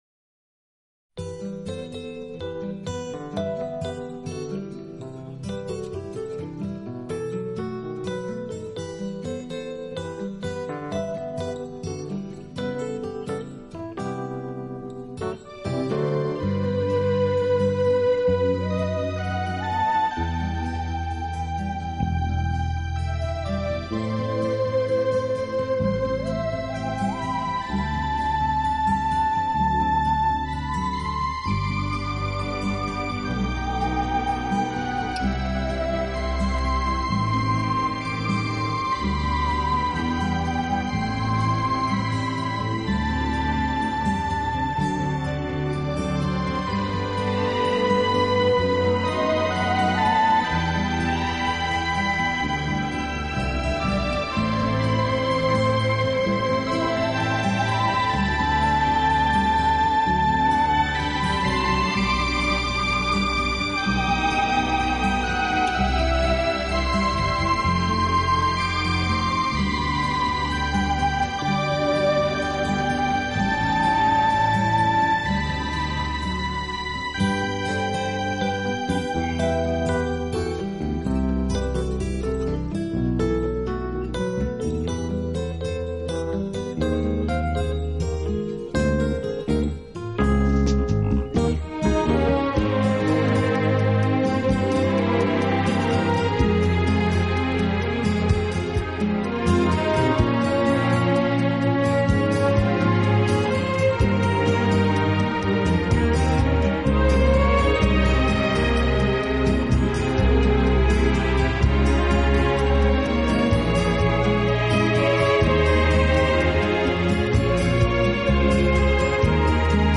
【轻音乐】
体，曲风浪漫、优雅，令人聆听時如感轻风拂面，丝丝柔情触动心扉，充分领略